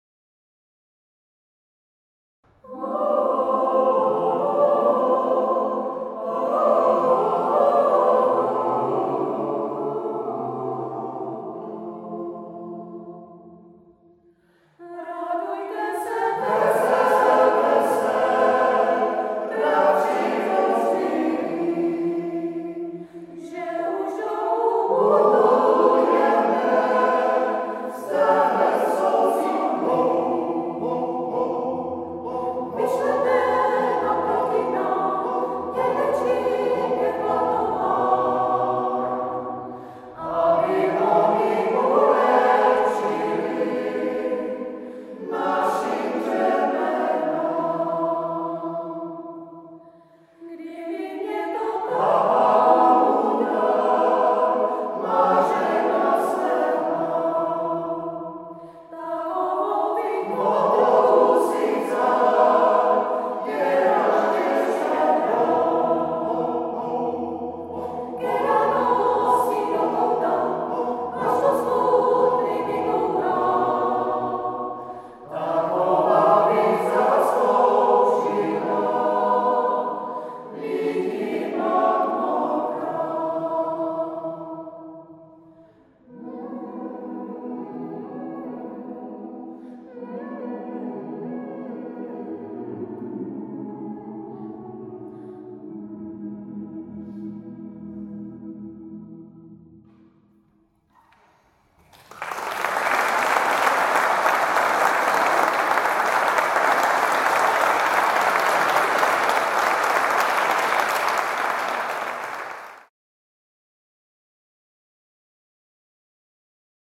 Zvuková ukázka z vystoupení ve Valle san Felice